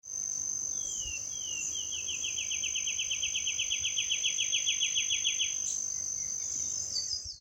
Arañero Ribereño (Myiothlypis rivularis)
Localidad o área protegida: Parque Provincial Urugua-í
Localización detallada: Arroyo Uruzú
Condición: Silvestre
Certeza: Vocalización Grabada
Aranero-ribereno.mp3